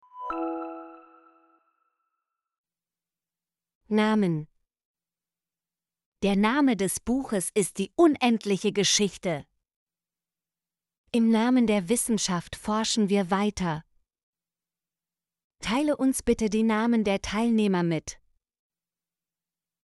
namen - Example Sentences & Pronunciation, German Frequency List